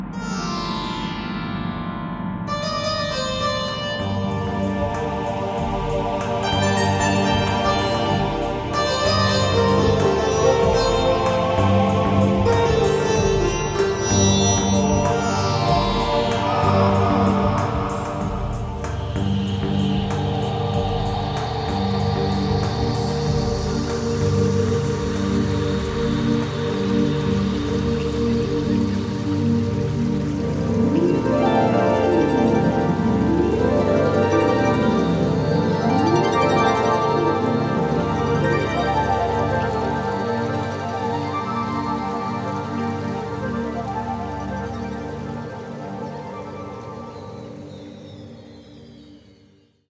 A reverent musical tribute to the Ascended Masters
a unique blend of Oriental & Western musical traditions
Mastered with 444 Hz Solfeggio Frequencies.